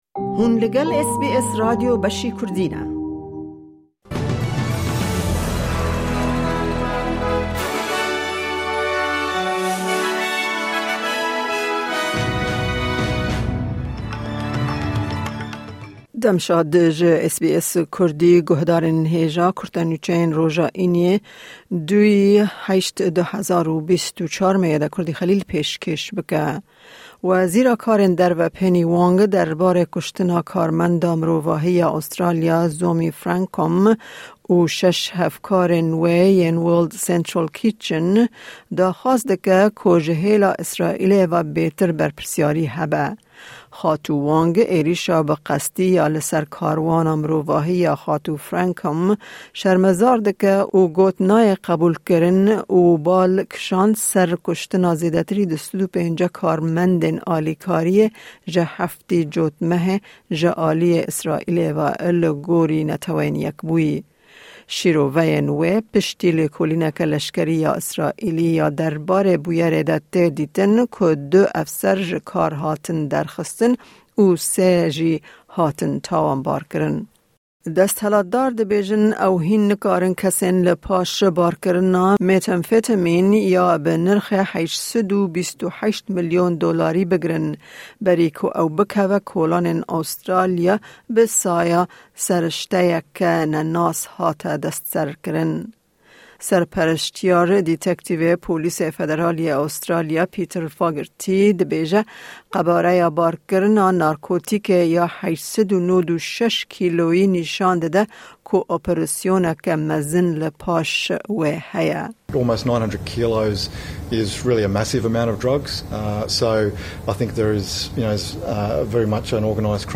Kurte Nûçeyên roja Înî 2î Tebaxa 2024